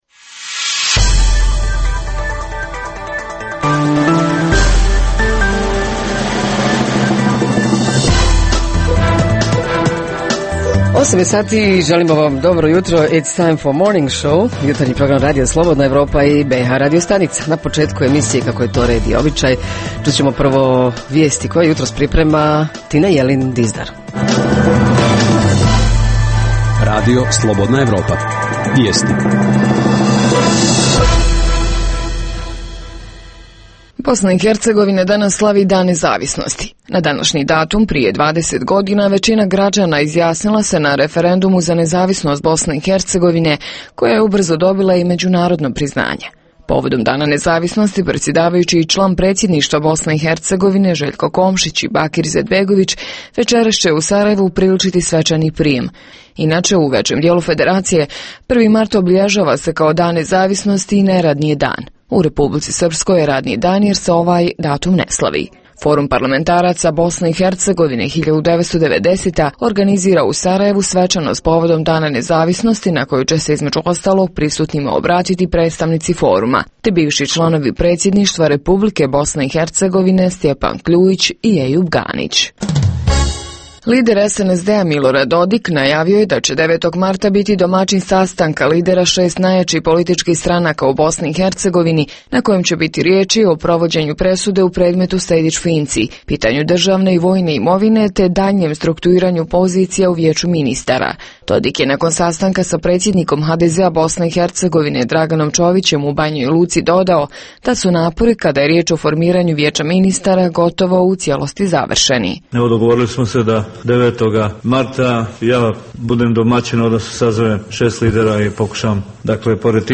Tema jutra: na koji način se u lokalnim zajednicama radi na podizanju ekološke svijesti kod građana? Reporteri iz cijele BiH javljaju o najaktuelnijim događajima u njihovim sredinama.
Redovni sadržaji jutarnjeg programa za BiH su i vijesti i muzika.